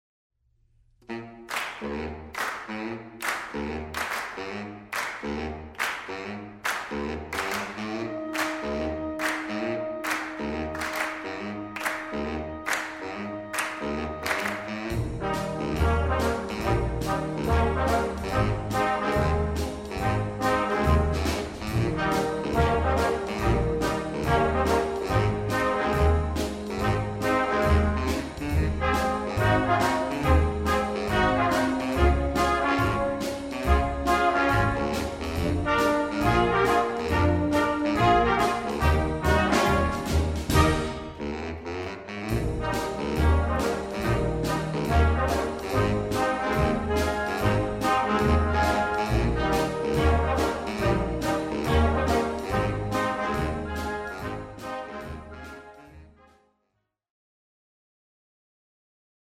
Banda de música
Ritmos modernos y de Jazz